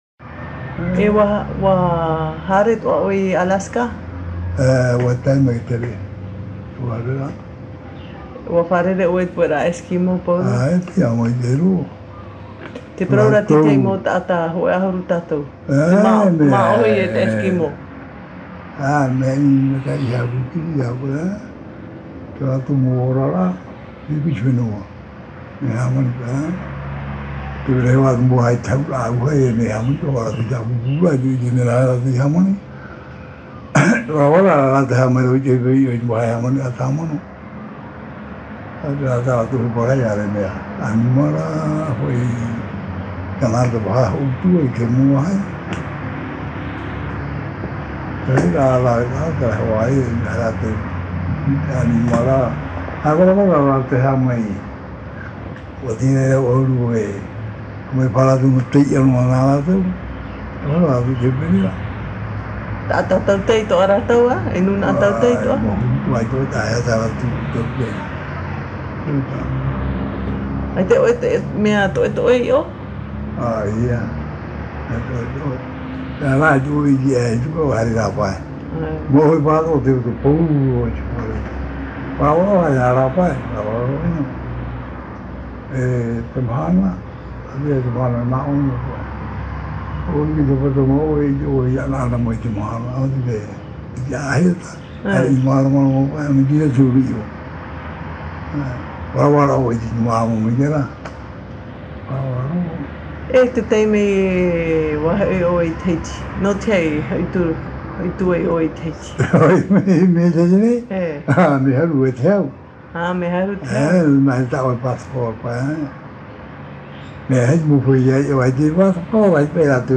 Interview réalisée à Pape'ete sur l’île de Tahiti.
Papa mātāmua / Support original : cassette audio